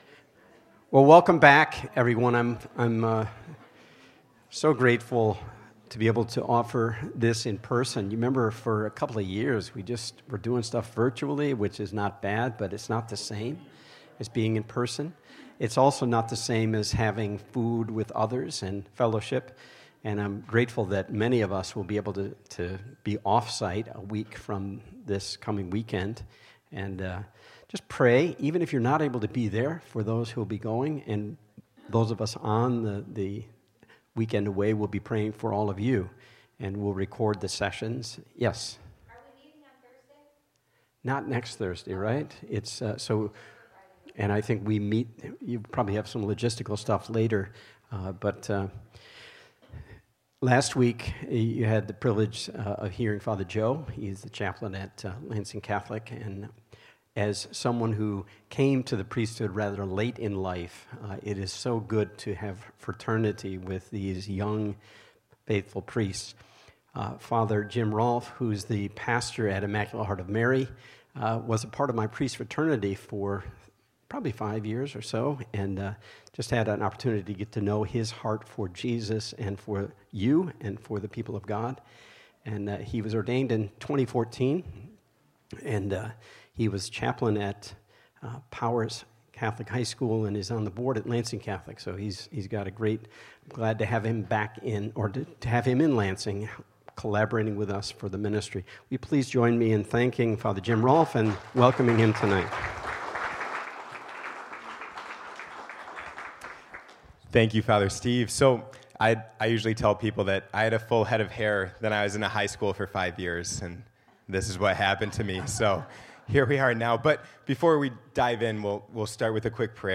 This 5-week course created by the Church of the Resurrection presents the wonderful message of the gospel and provides participants with a way of opening themselves to the powerful and liberating experience of new life in Christ through the Holy Spirit. Below is the audio of the talks.